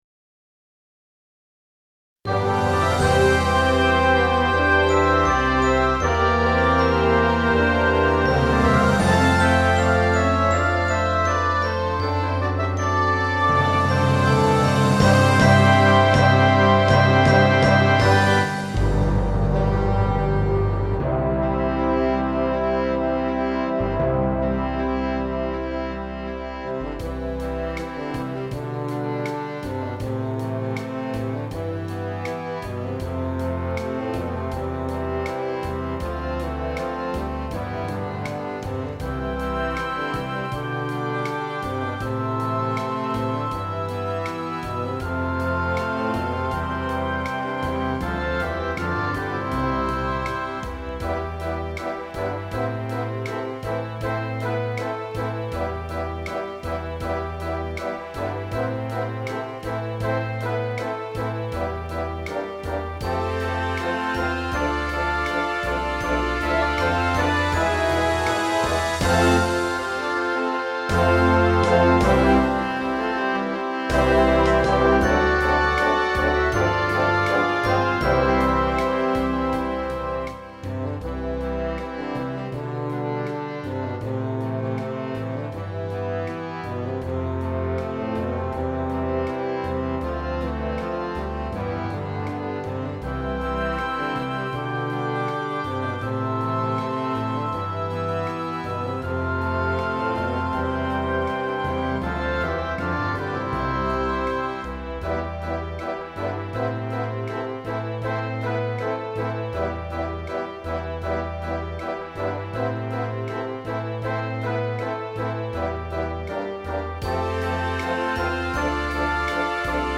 吹奏楽 C Major版
C Major
（エンディングは D-flat Major）
MIDIによる演奏
（歌なし）